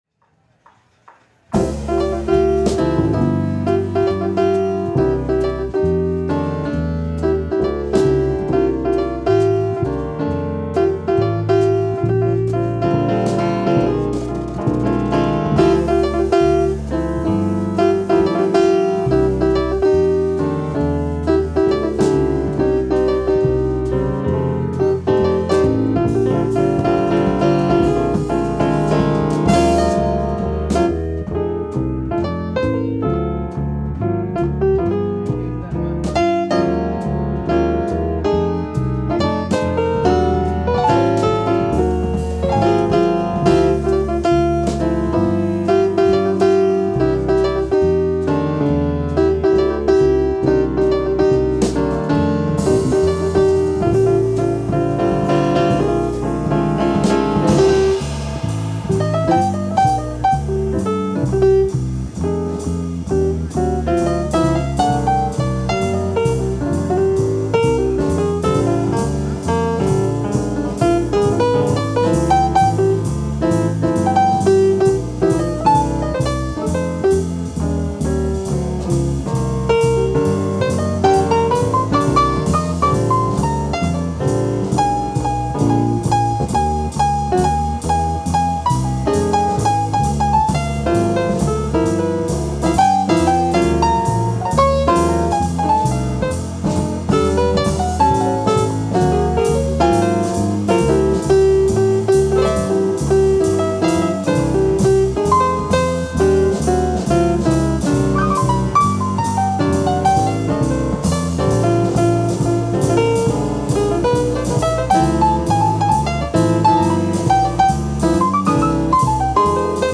Жанр: Jazz Vocals.